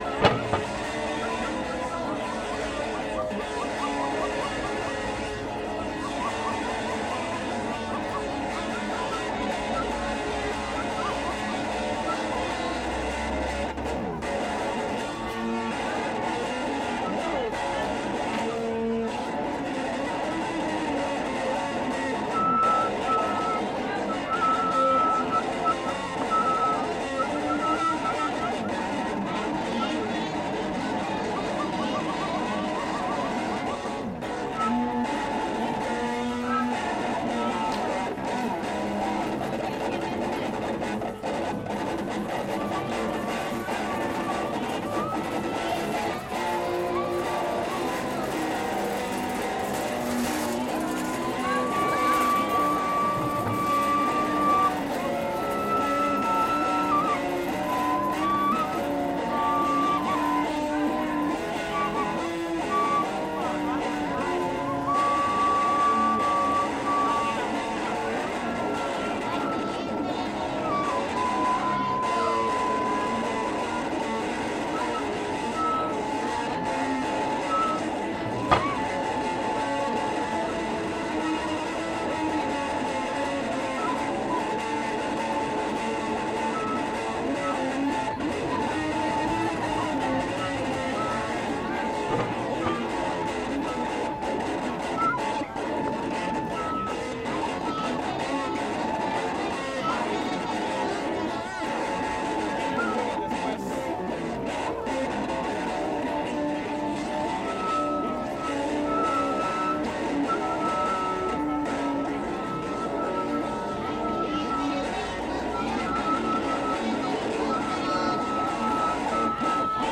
La noche estaba fría y con llovizna. Había poco tránsito de personas por lo que el sonido se dispersaba más por el espacio.
Rock etnico callejero ACM 2018.mp3